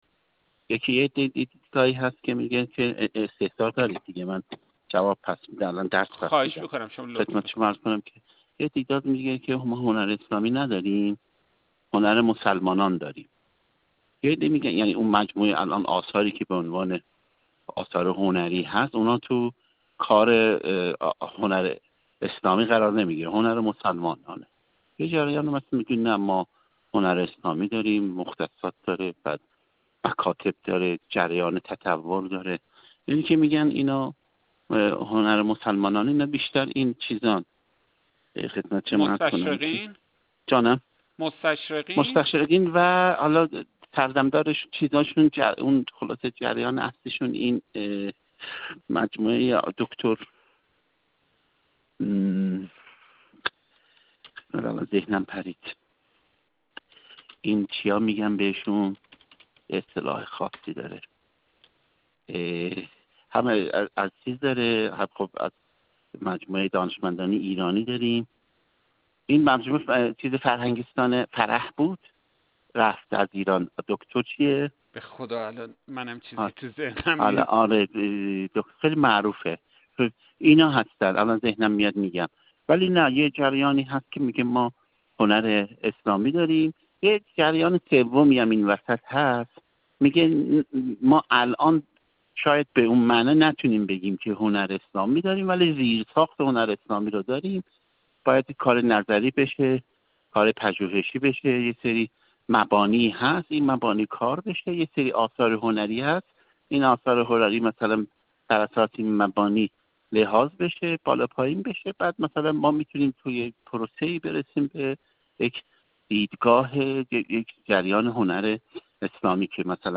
گفت‌و‌گو